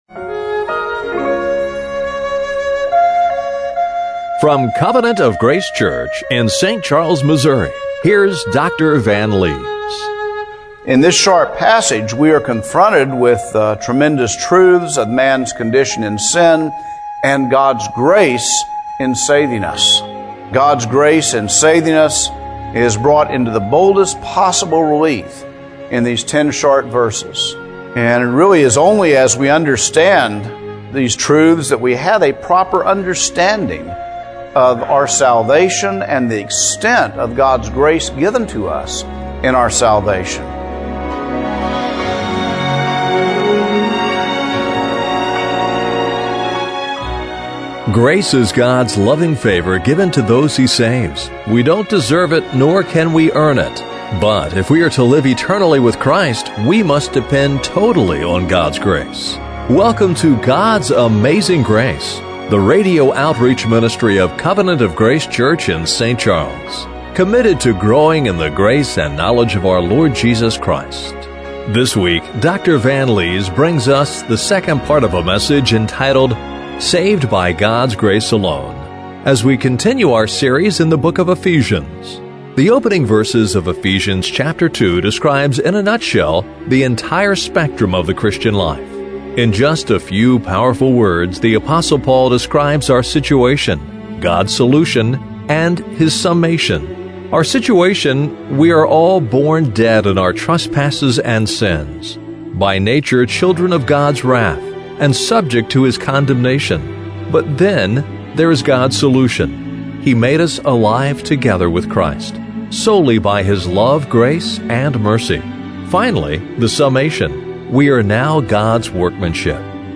Ephesians 2:1-10 Service Type: Radio Broadcast How has your life been impacted by the glorious biblical truth of salvation by God's grace alone?